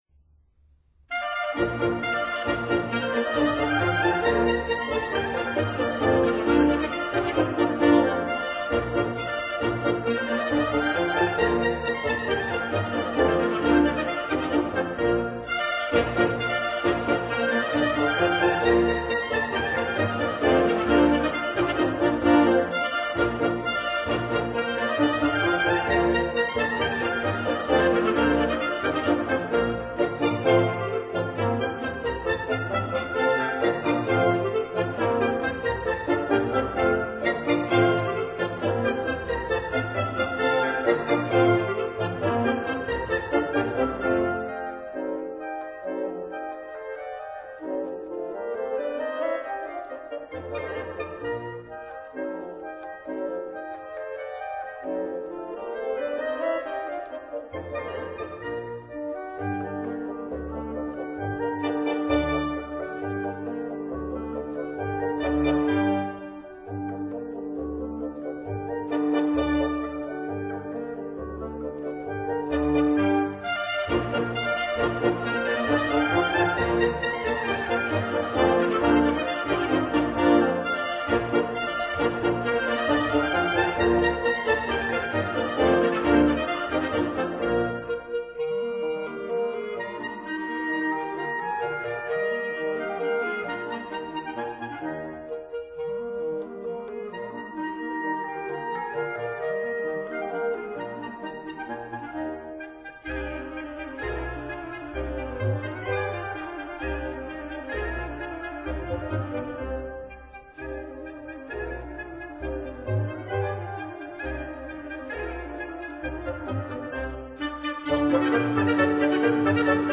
07 Wind Serenade in B-flat major
oboes
clarinets
bassett horns
bassoons
double bass